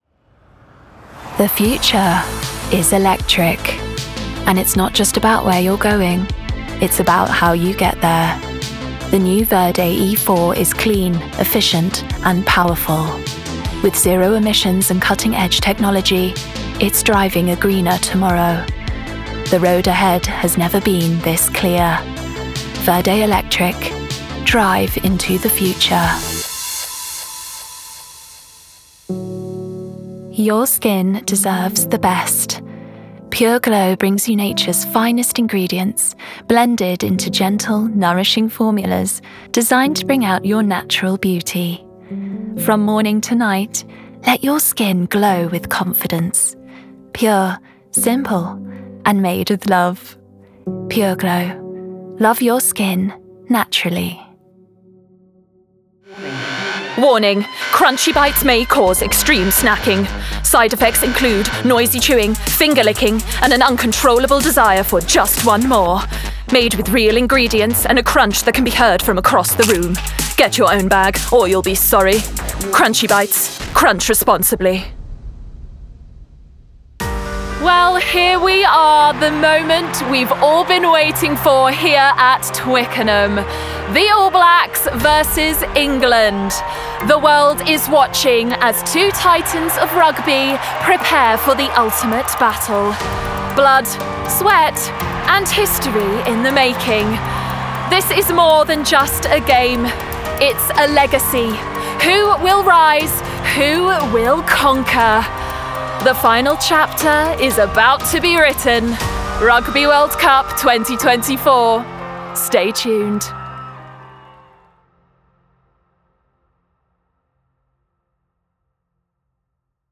Commercial Demo:
With a vocal profile that is resonant, sincere and a little cheeky, I offer a vibrant and youthful natural RP voice for commercials, video games, animated characters and audiobooks alike.
Resonant, warm, sincere, playful.
Modern British/ Received Pronunciation.